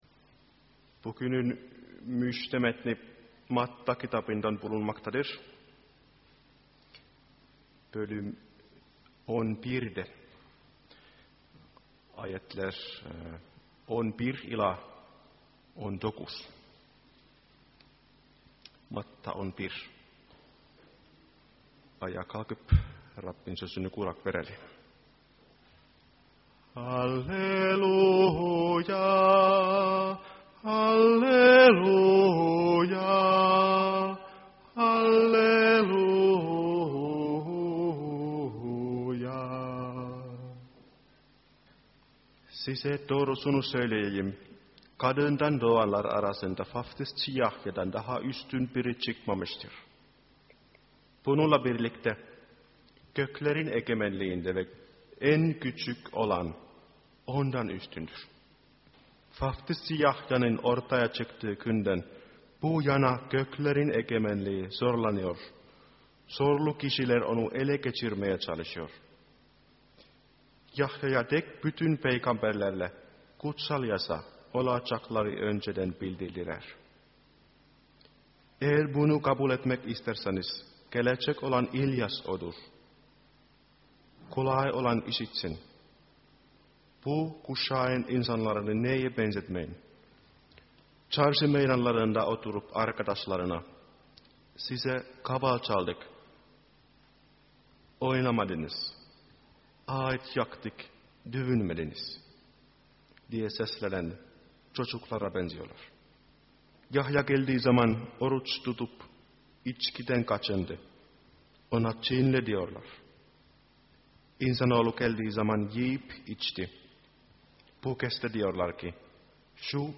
2013 yılı: Matta Kitabından vaazlar